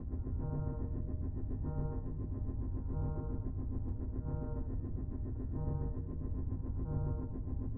Beam sounds
beam_loop.wav